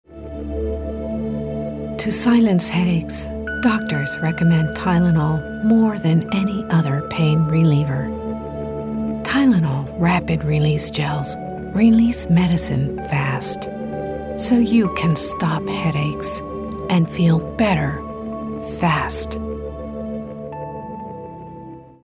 a smooth and resonant voice
Pain reliever (serious, dramatic)